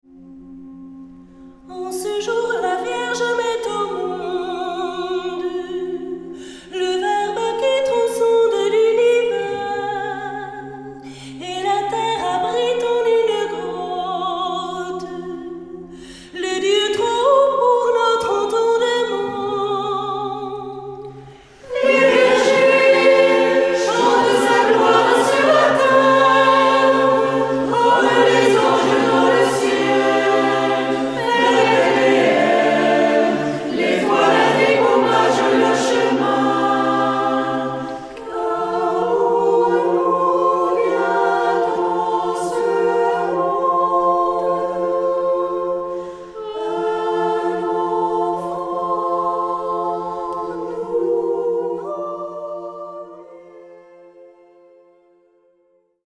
chantent à quatre voix les plus beaux chants de Noël
Format :MP3 256Kbps Stéréo